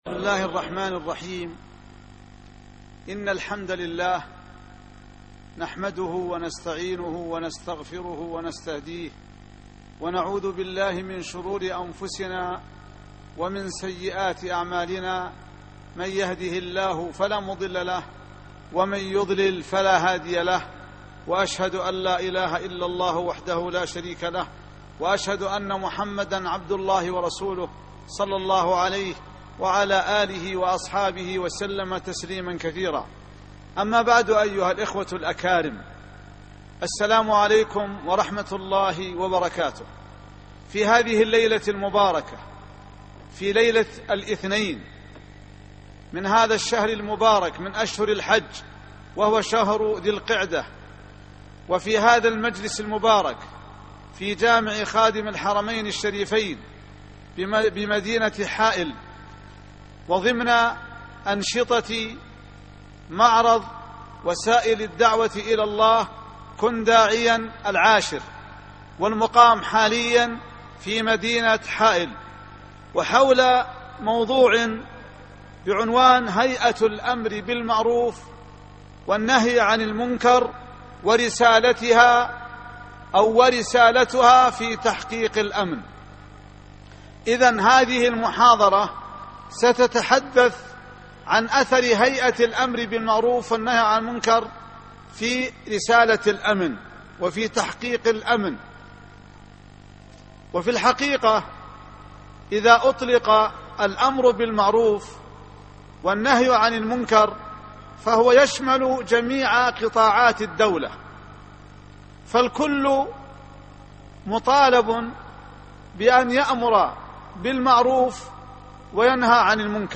أثر هيئة الأمر بالمعروف في تحقيق الأمن (22/10/2010) محاضرة اليوم - الشيخ إبراهيم بن عبد الله الغيث